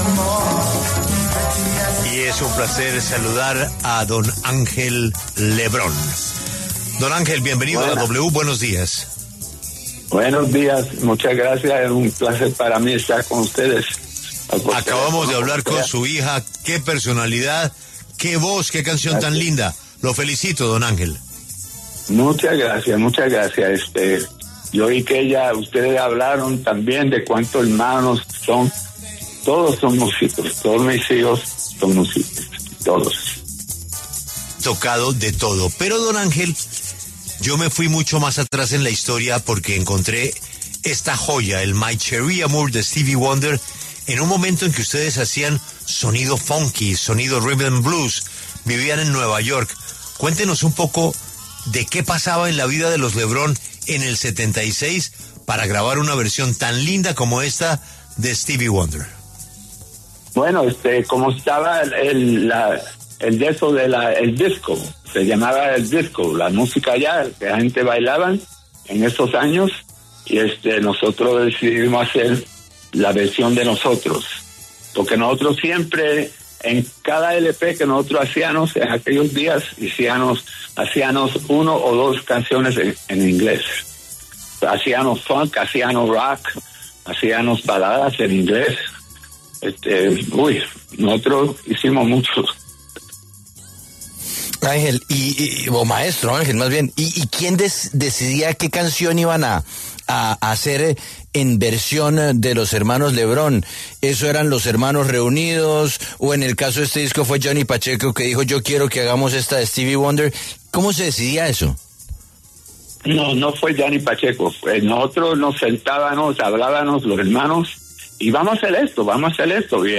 Ángel Lebrón pasó por los micrófonos de La W para hablar sobre lo que ha sido su trayectoria artística, y cómo en su juventud, junto a los ‘Hermanos Lebrón’, fueron disruptivos.